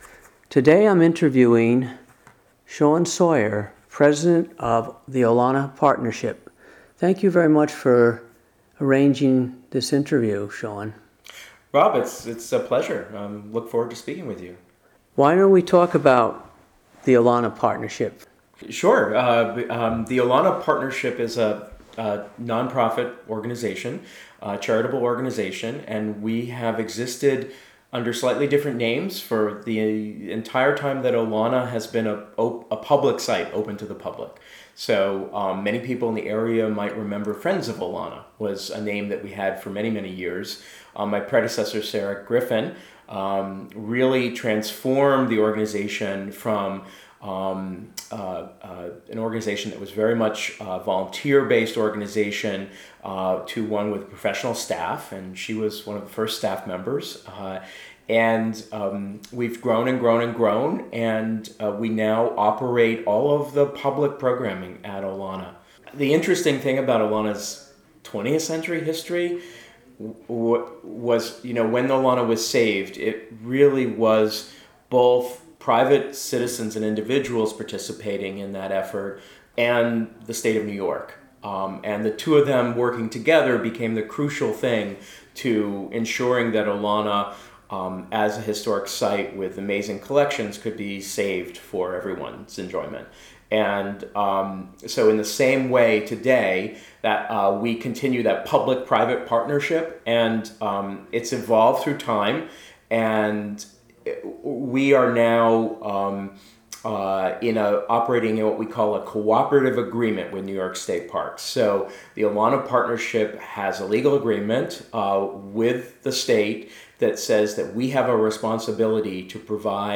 The interview was recorded on July 2, 2019 at Olana.